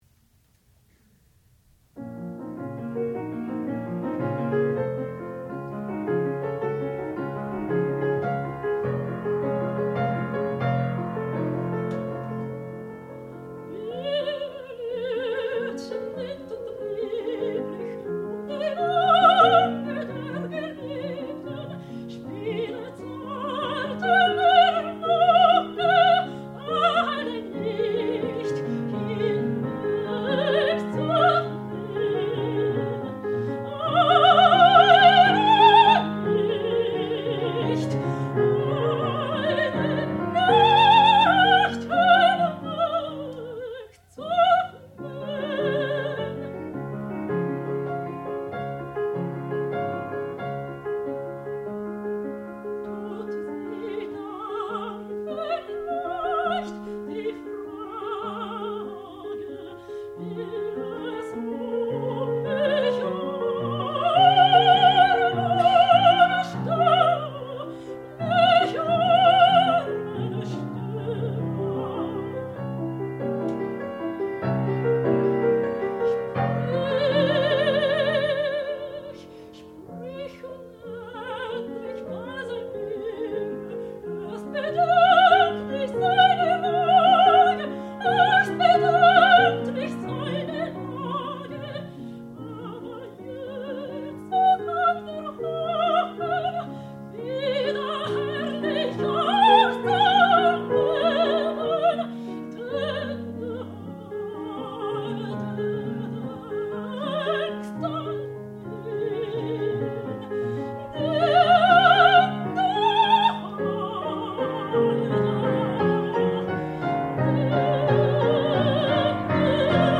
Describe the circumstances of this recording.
Master's Recital